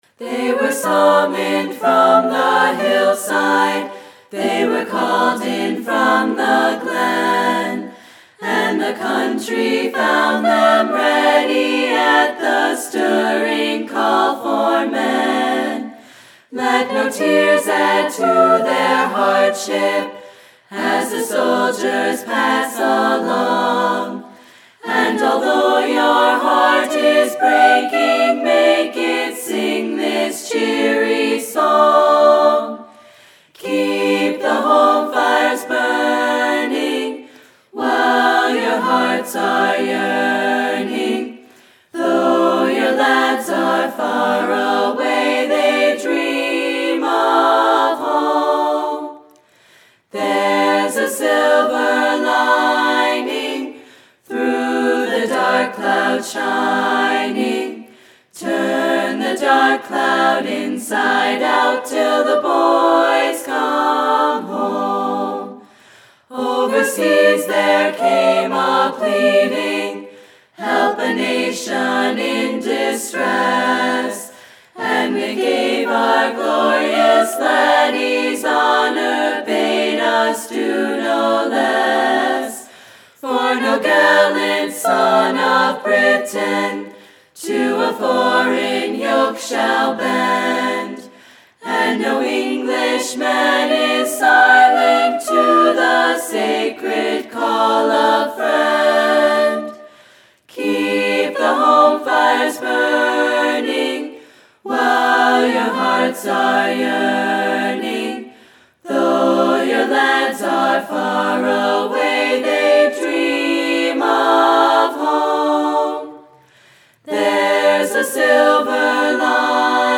Click on the "Listen (MP3)" button to hear the song performed by the EC Chiclettes where available.